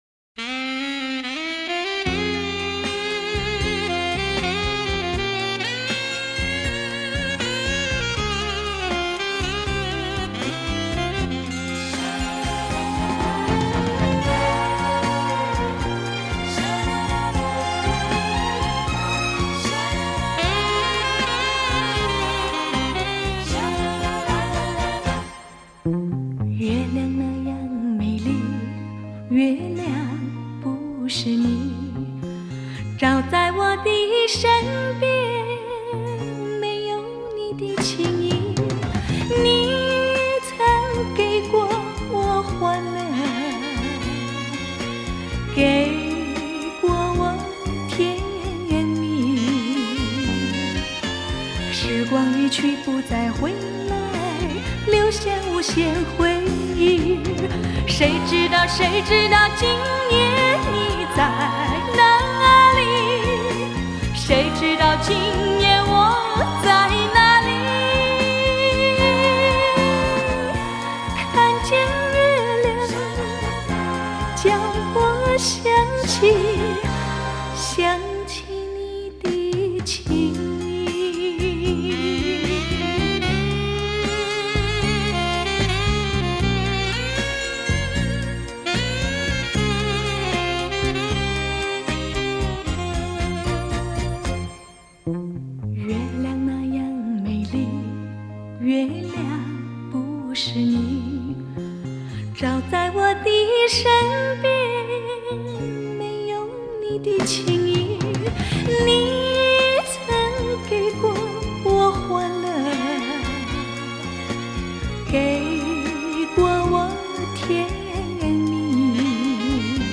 以当今至高规格音效处理